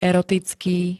erotický [-t-] -ká -ké 2. st. -kejší príd.
Zvukové nahrávky niektorých slov